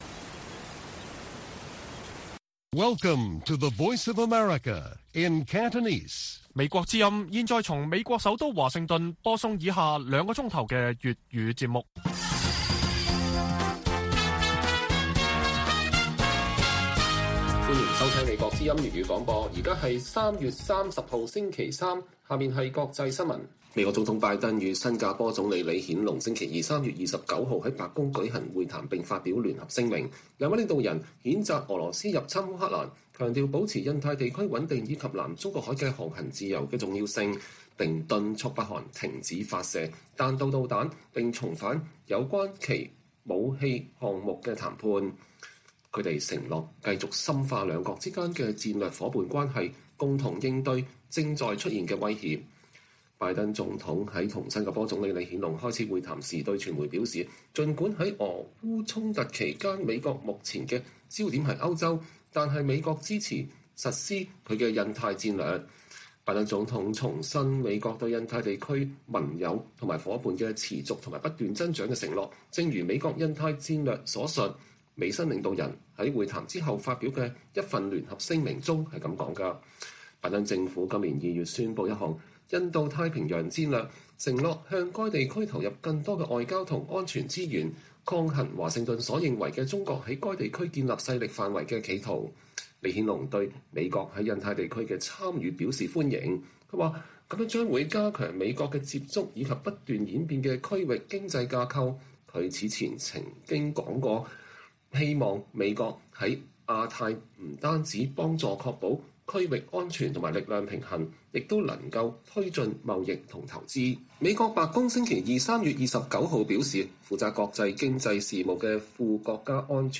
粵語新聞 晚上9-10點: 港府調高違反強檢罰則最高可監禁半年 前議員批高壓措施無助抗疫